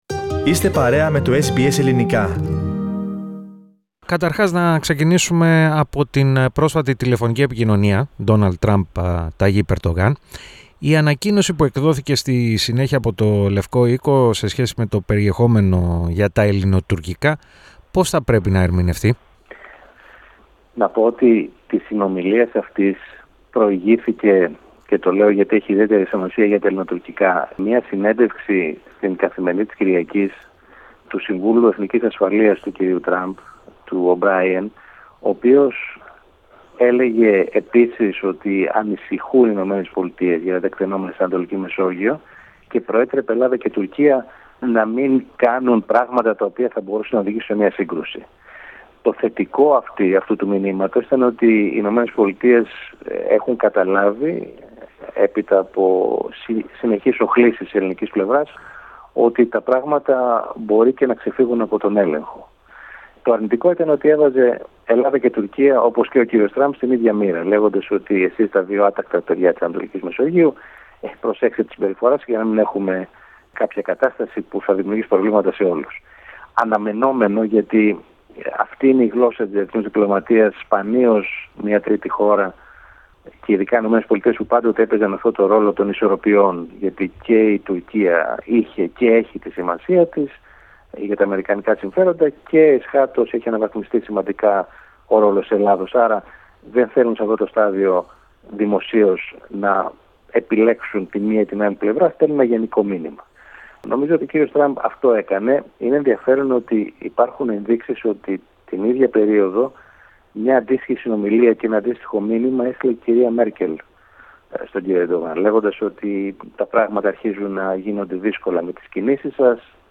Την εκτίμηση του για το ενδεχόμενο πρόκλησης ενός θερμού επεισοδίου μεταξύ Τουρκίας και Ελλάδας, διατύπωσε στο Ελληνικό Πρόγραμμα της ραδιοφωνίας SBS, ο αναπληρωτής σύμβουλος Εθνικής Ασφαλείας, της Ελλάδας, Θάνος Ντόκος.